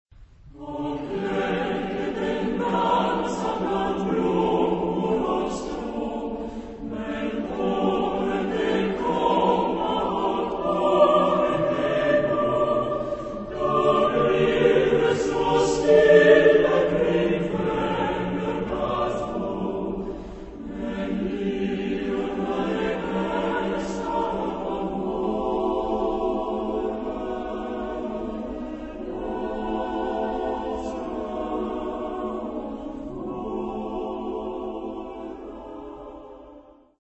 Epoque: 20th century
Genre-Style-Form: Secular
Type of Choir: SATB  (4 mixed voices )